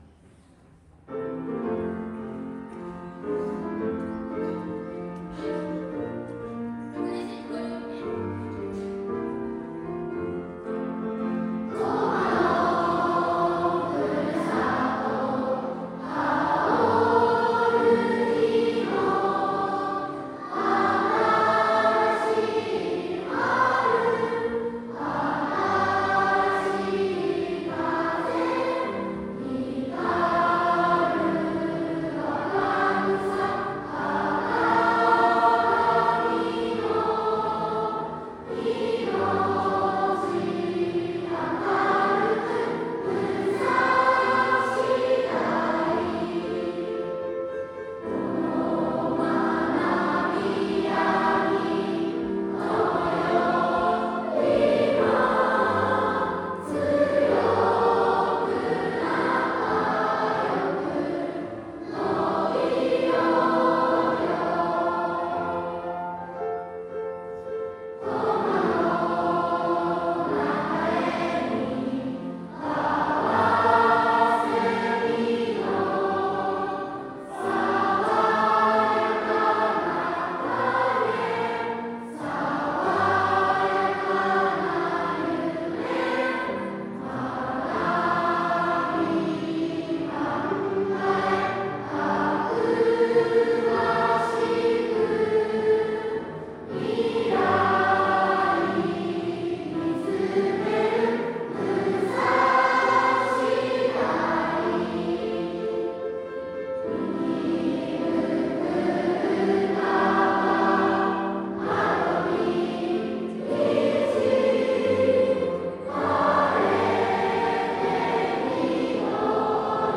• ・４月２３日（火）音楽朝会
体育館の中に１年生から９年生までの
• 歌声が響き渡りました。